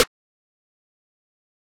REDD BEST SNARE 3.wav